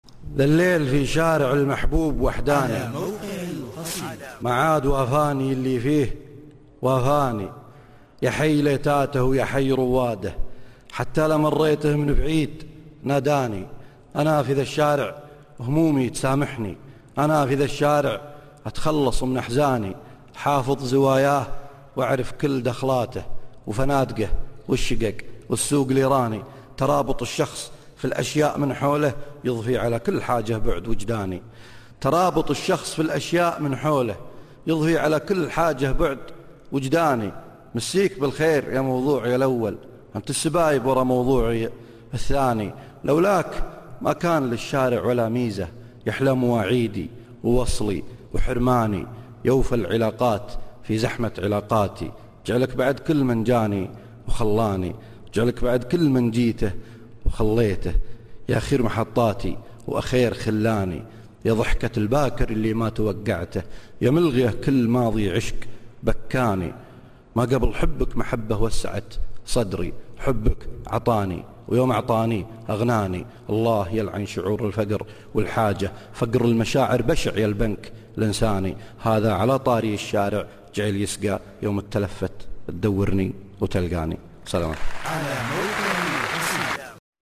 الشارع (ملتقى دبي 2012)   0/ 5